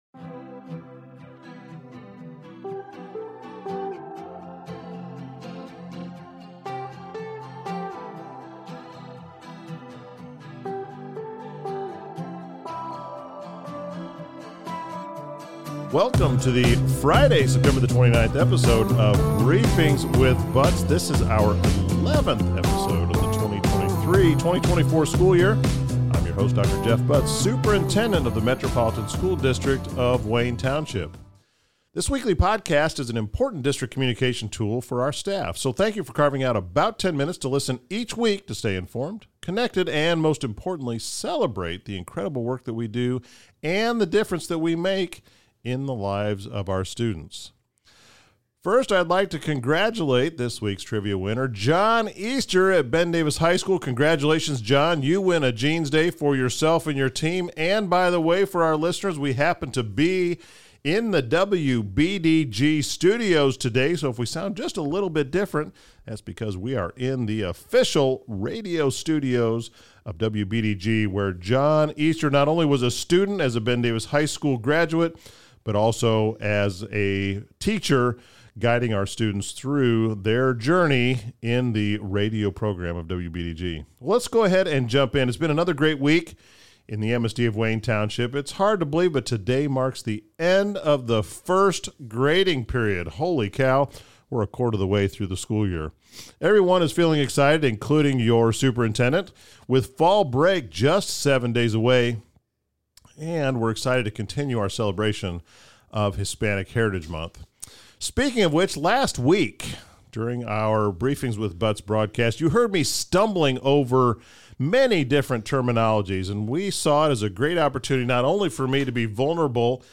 During his interview segment